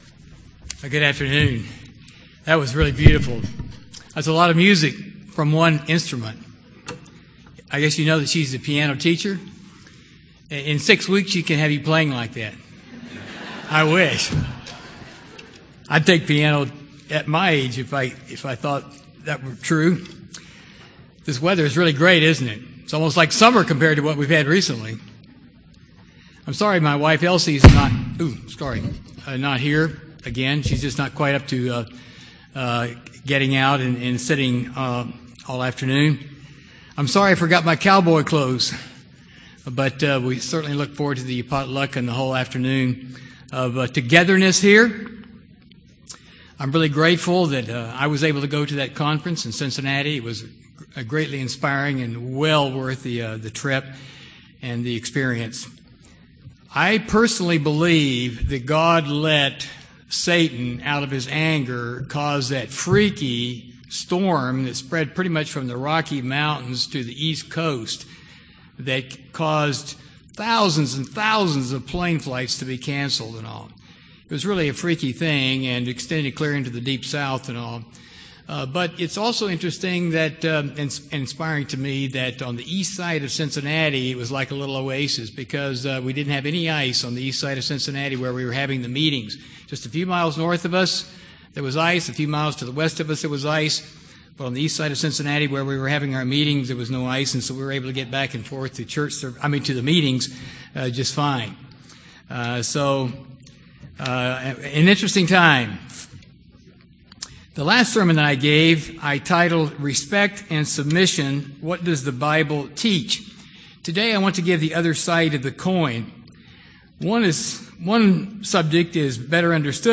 This sermon is # 2 of a two-part series on authority/submission—one helps to understand the other. What is the biblical perspective on exercising authority?